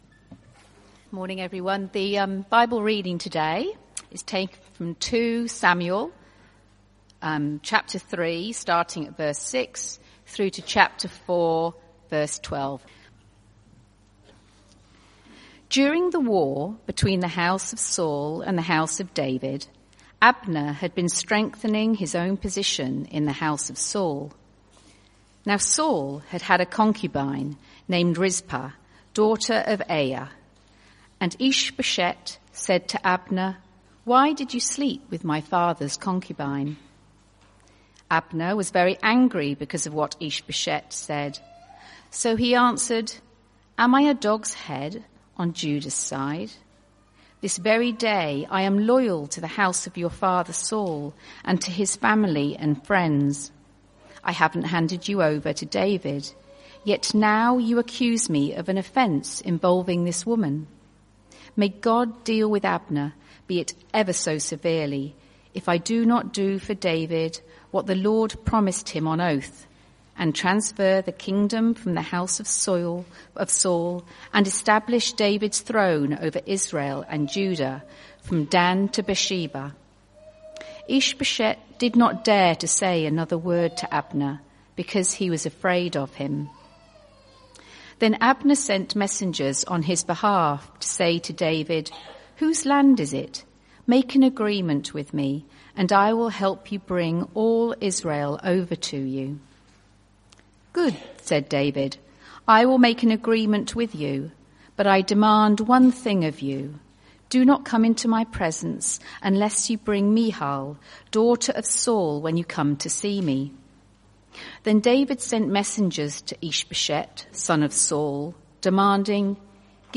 CBC Service: 30 June 2024 Series
Type: Sermons